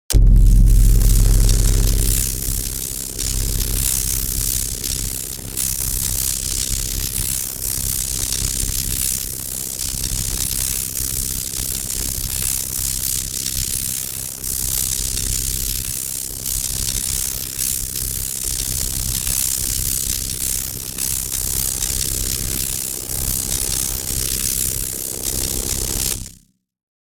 Unstable Energy Source, Energy Field 5 Sound Effect Download | Gfx Sounds
Unstable-energy-source-energy-field-5.mp3